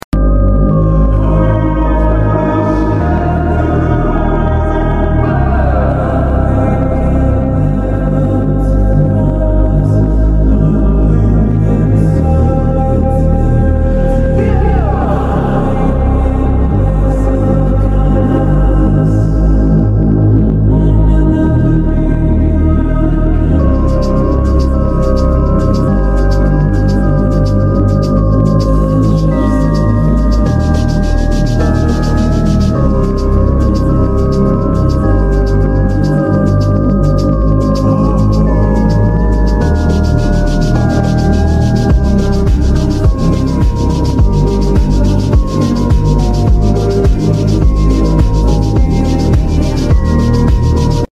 This sound signifies a dark and profound realisation.
this song is like the world is ending like out of a movie